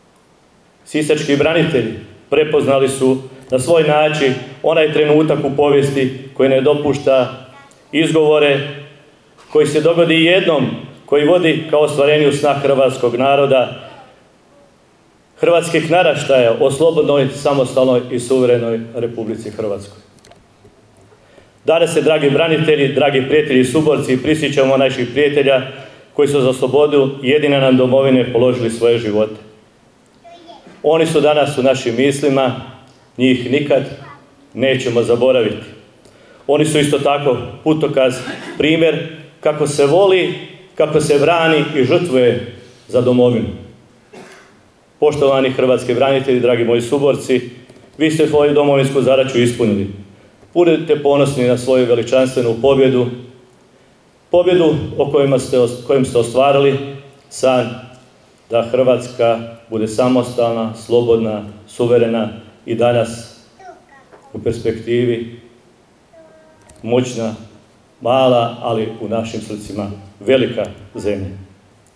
General u miru, u ovoj prigodi izaslanik predsjednika Vlade RH i potpredsjednika Vlade i ministra obrane, državni tajnik Drago Matanović podsjetivši na početak Domovinskog rata i ulogu branitelja Siska u obrani Domovine je istaknuo